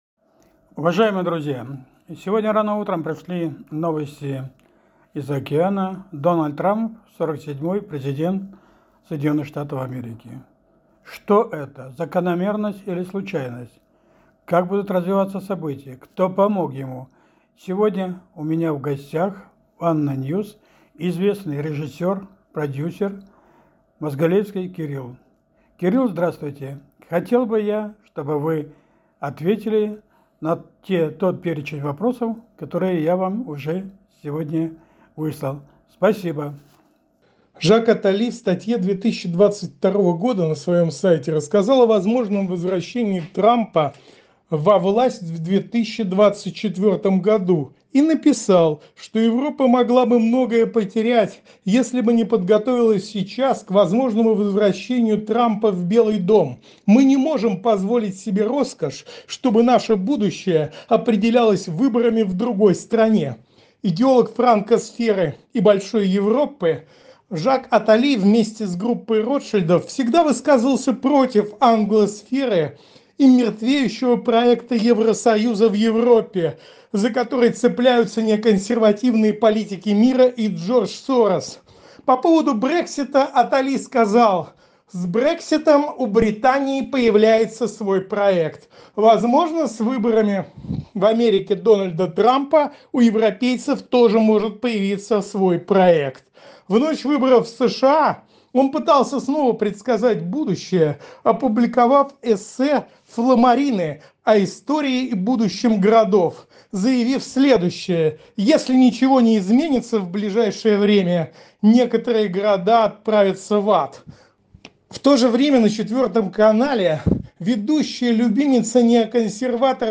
Обо всем этом в эфире радио ANNA NEWS побеседовали